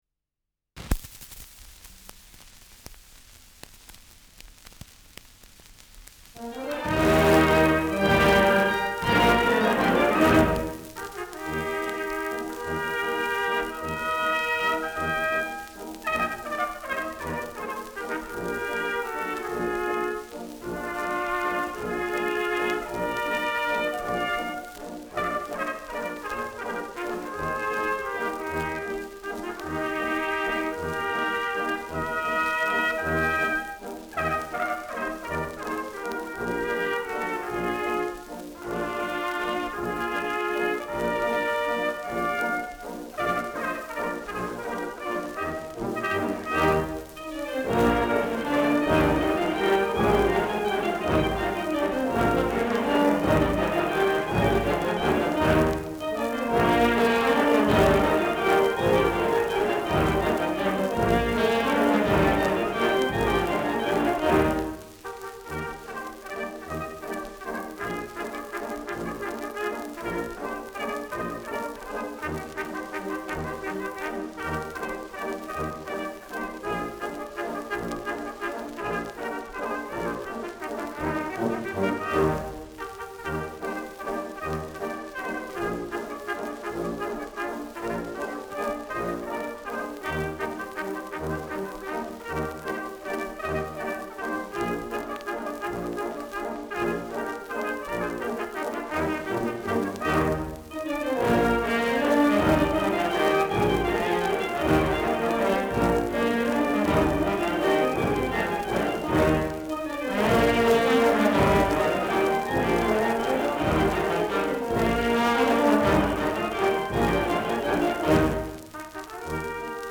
Schellackplatte
[München] (Aufnahmeort)